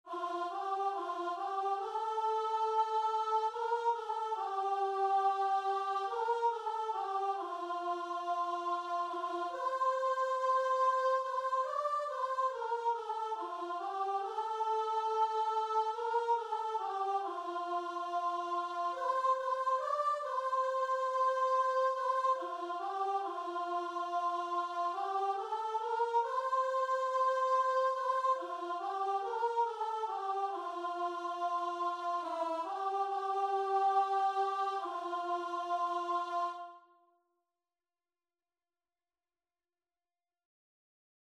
Choir version
4/4 (View more 4/4 Music)
Choir  (View more Easy Choir Music)
Classical (View more Classical Choir Music)